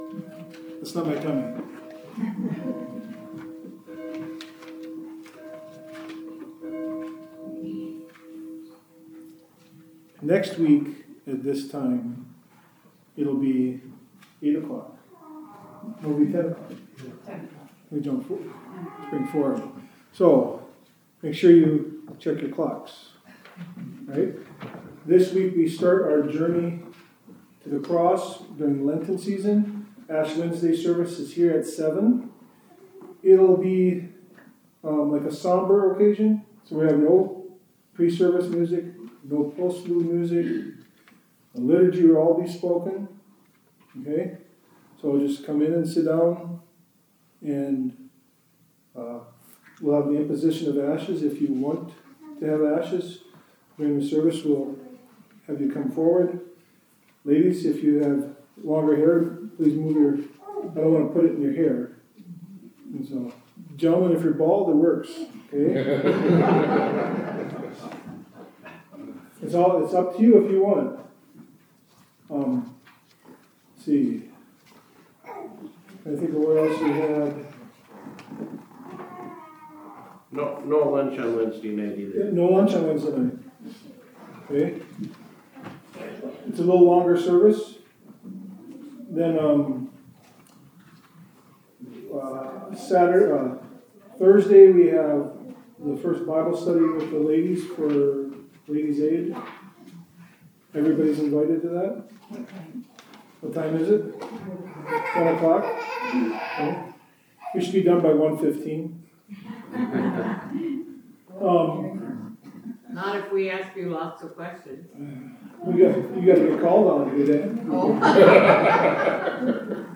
Immanuel Worship 2 Mar 25
Immanuel-Worship-2-Mar-25.mp3